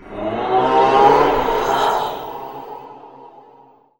shout.wav